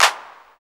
SD C30.wav